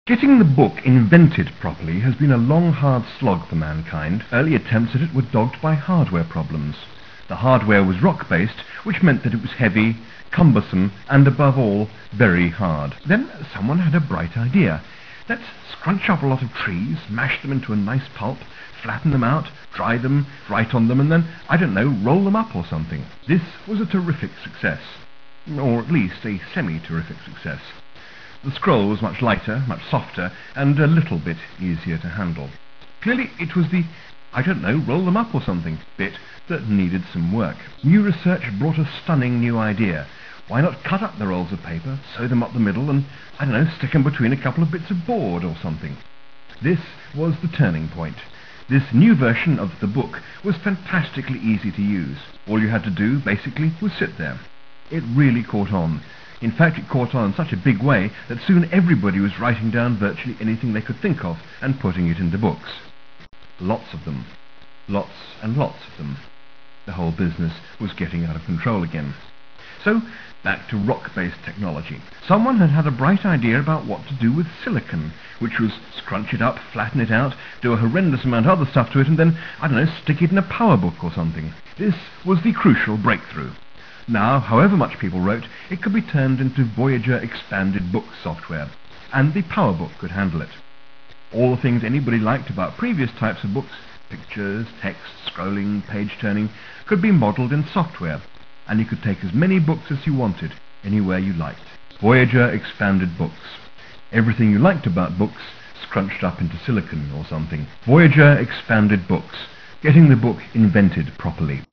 A big Mac fan, and a Voyager fan as well, Adams wrote and recorded a short promotional audio for the floppy-disk-based ebook series. Here, in all of its crunchy 8-bit-audio glory, is that recording,